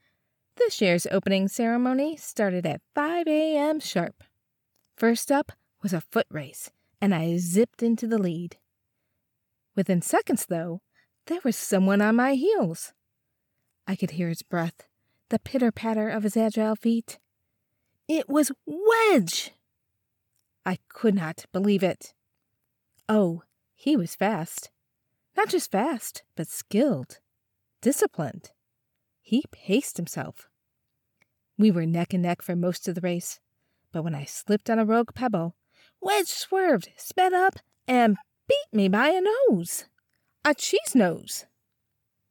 Home Studio Specs: AT2020USB+ Cardioid Condenser USB Mic, Audacity, DropBox or WeTransfer.
Warm, Grounded, Midwest accent
Clear and intuitive